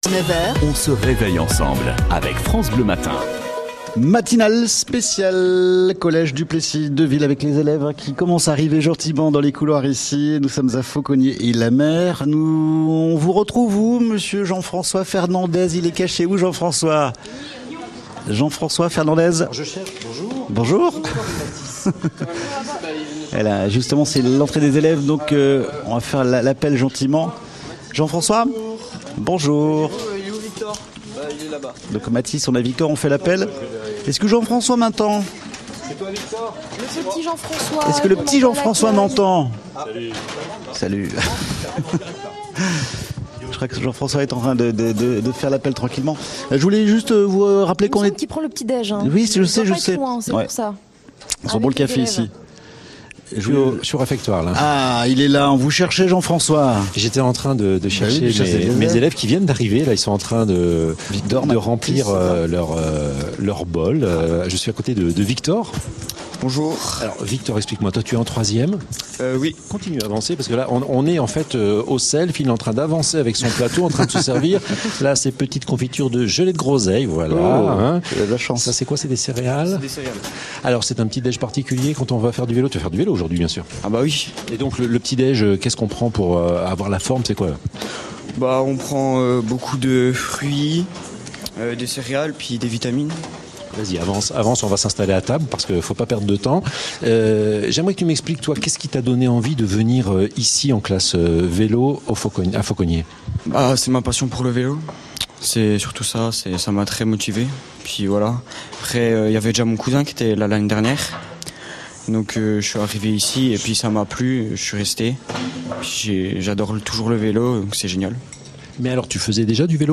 Vendredi 18 octobre, France Bleu Besançon avait installé ses studios au collège de Faucogney-et-la-Mer de 6h à 13h.